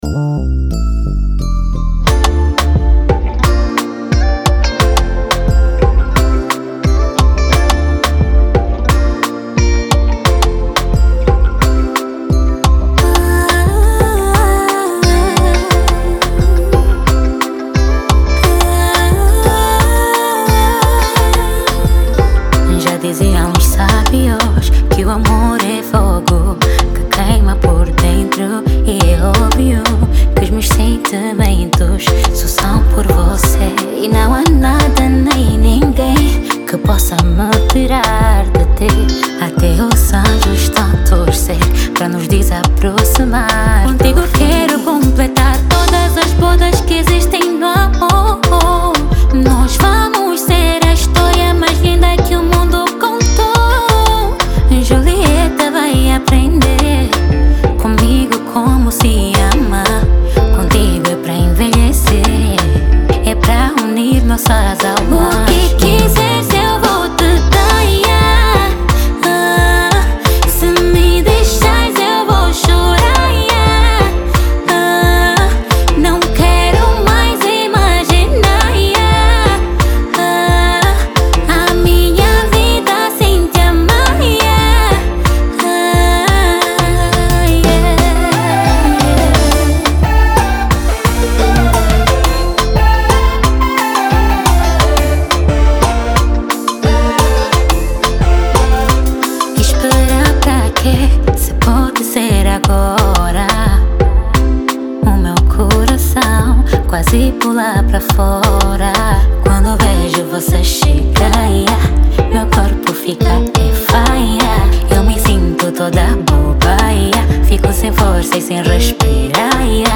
Genero: Kizomba